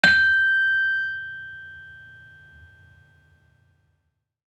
HSS-Gamelan-1
Saron-5-G5-f.wav